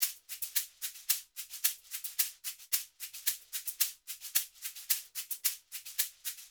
WVD SHAKER 2.wav